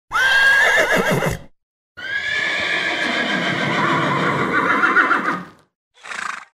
دانلود صدای شیهه اسب 2 از ساعد نیوز با لینک مستقیم و کیفیت بالا
جلوه های صوتی